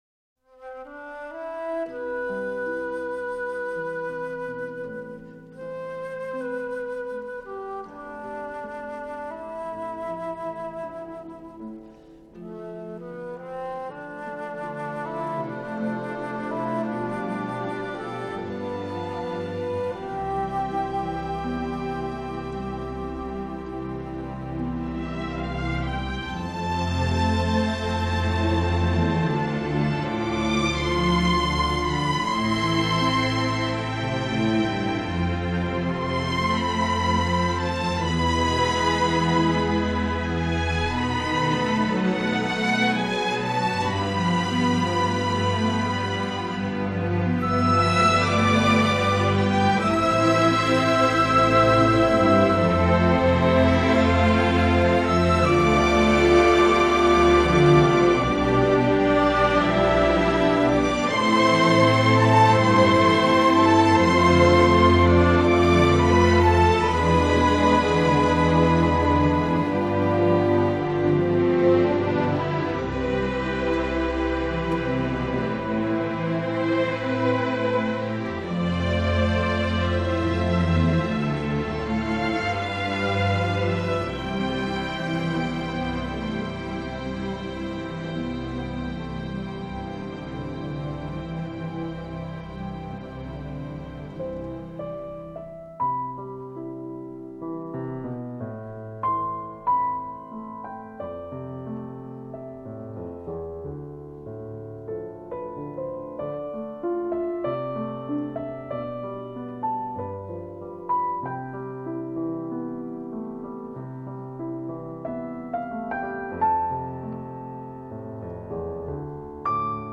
在宁静的竖琴伴随下，柔和的长笛独奏出了一支富于歌唱性的旋律。
弦乐不露锋芒地开始进入的时候，带来一种温暖、缠绵的感觉，
随即以明亮的音色替代了长笛，抒发出心中难以遏制的激情。